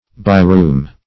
By-room \By"-room`\, n.